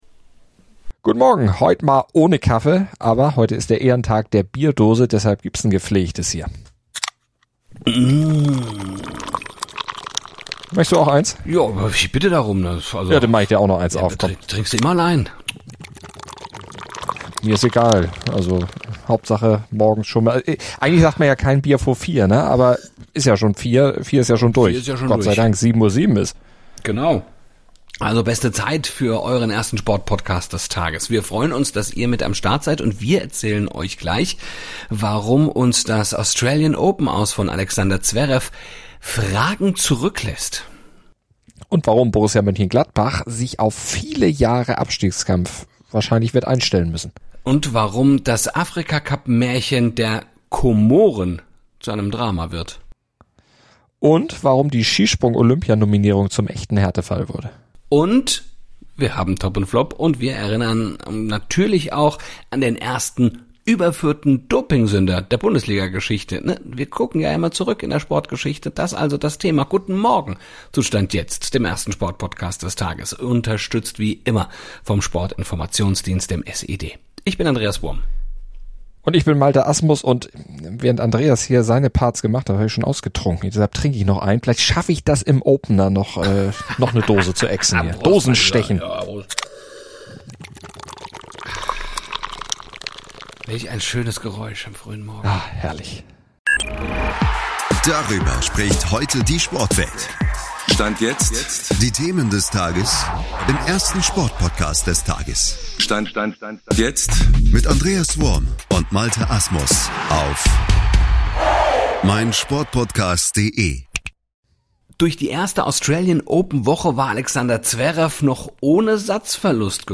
Dazu gibt es Top und Flop und unsere Moderatoren erinnern an den ersten überführten Dopingsünder der Bundesliga-Geschichte.